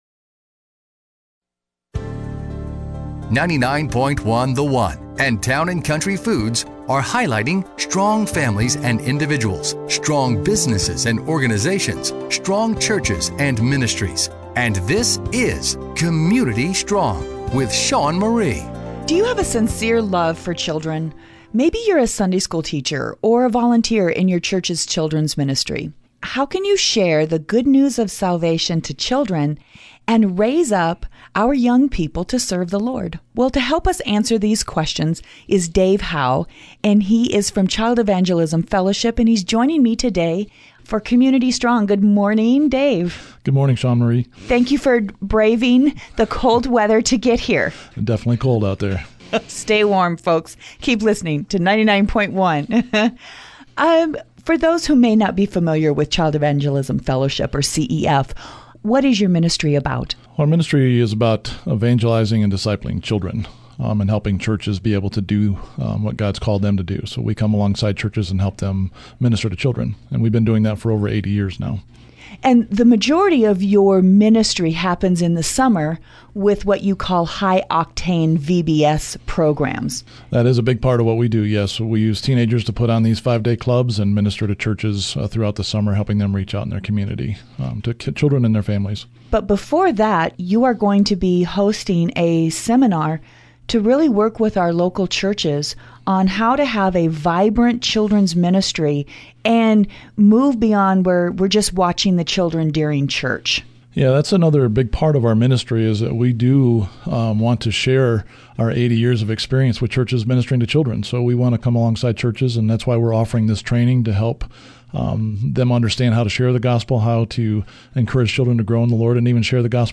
Childlike Gospel Training Radio Interview on 99.1
The interview was aired during their Community Strong segment.